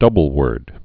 (dŭbəl-wûrd)